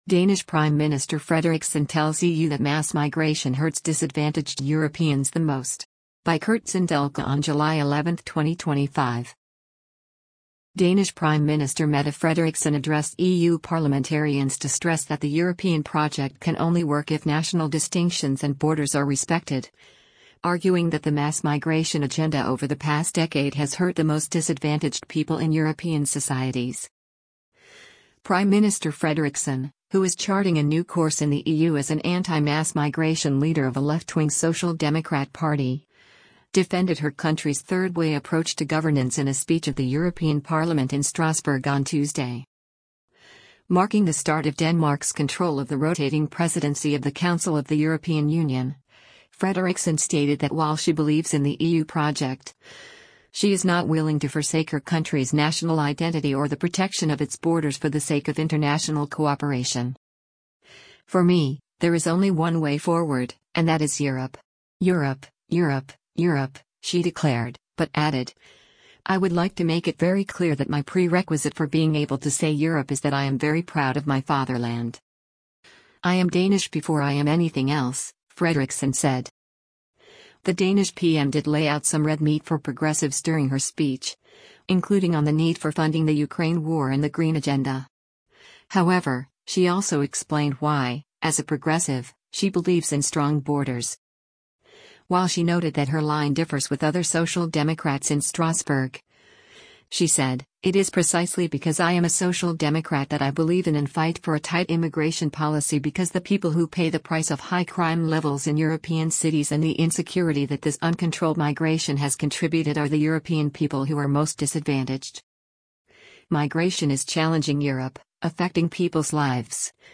Prime Minister Frederiksen, who is charting a new course in the EU as an anti-mass migration leader of a left-wing Social Democrat party, defended her country’s third-way approach to governance in a speech at the European Parliament in Strasbourg on Tuesday.
During her address, Frederiksen quipped several times about how her novel policy approach drew either applause from the left or the right, depending on the topic.